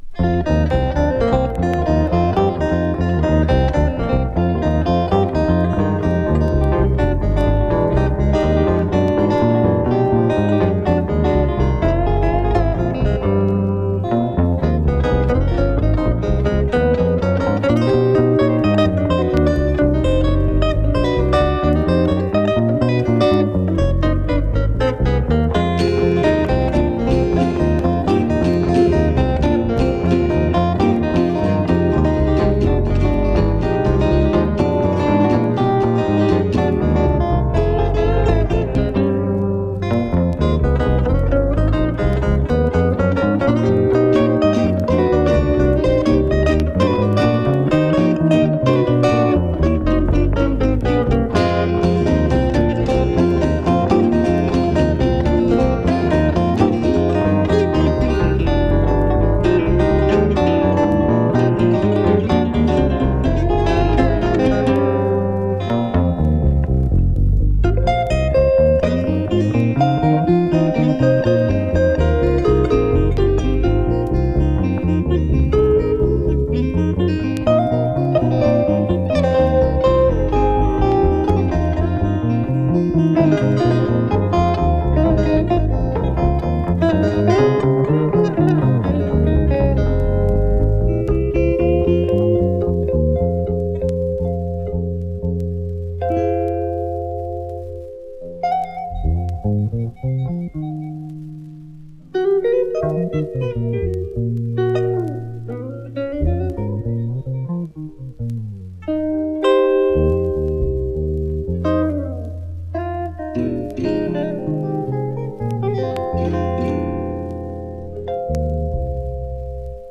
3. > PSYCHEDELIC/PROGRESSIVE/JAZZ ROCK